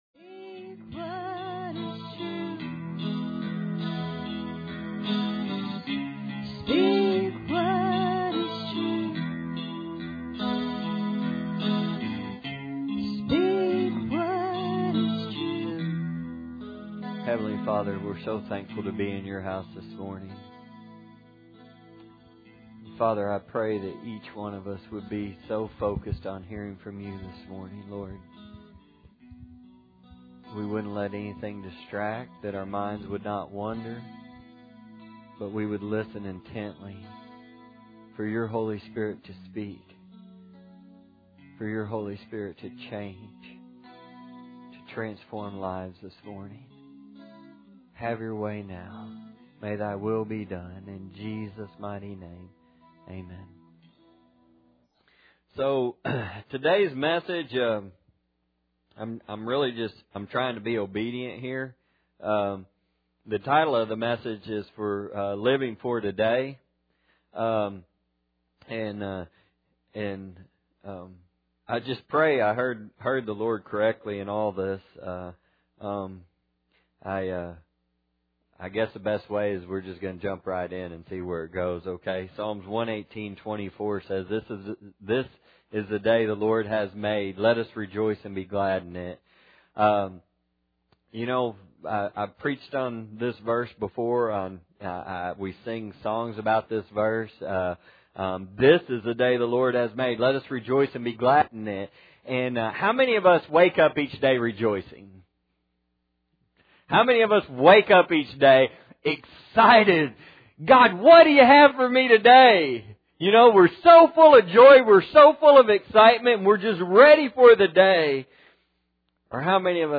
Matthew 6:25 Service Type: Sunday Morning Bible Text